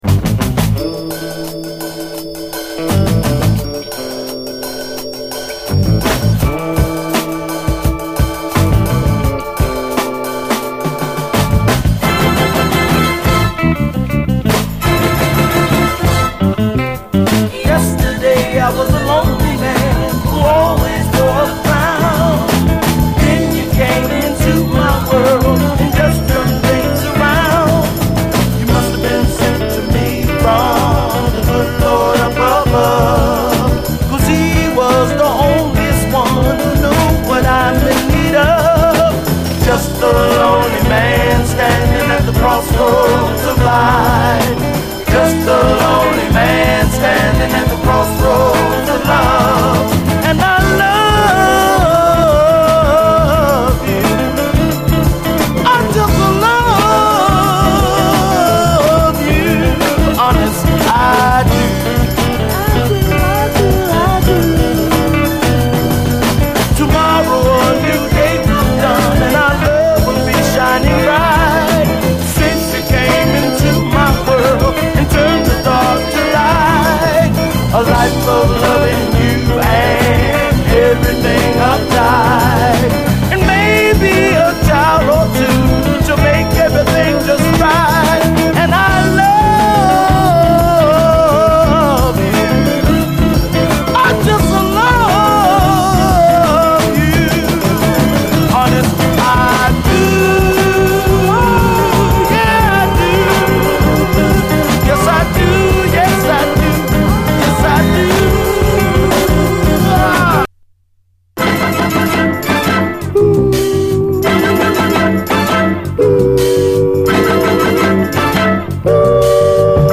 北欧アイスランド産のレア・ブルーアイド・ソウル〜S.S.W.盤！
メロウ・ボッサ